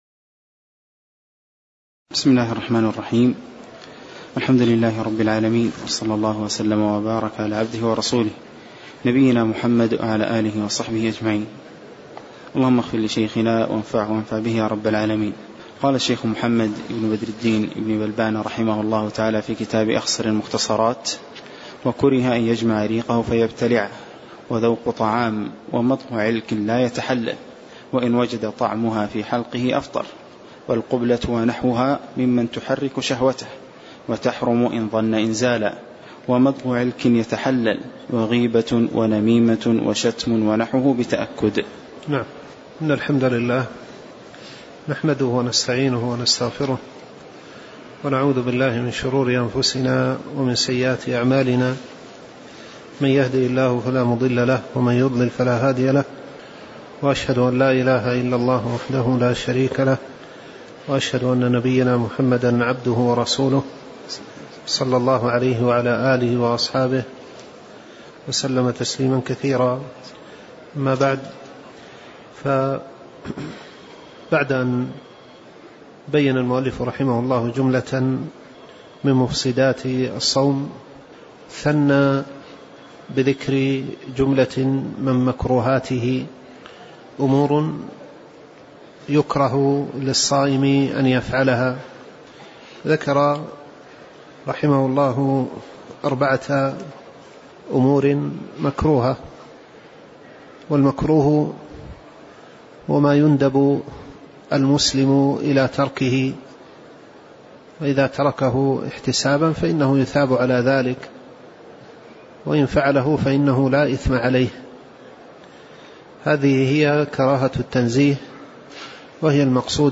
تاريخ النشر ٢٥ شعبان ١٤٣٩ هـ المكان: المسجد النبوي الشيخ